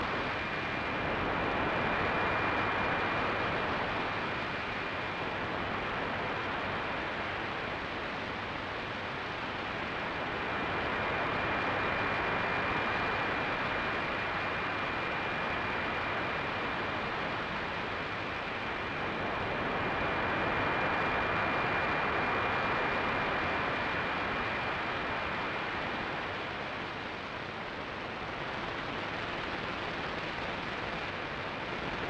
无线电静音 " 无线电静音短波噪音1
描述：录音链 Sangean ATS808 Edirol R09HR一些收音机的静电，可能对某人有用，在某个地方 :)录音链 Sangean ATS808 Edirol R09HR
Tag: 噪声 无线电静电 短波 调谐